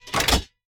select-cannon-1.ogg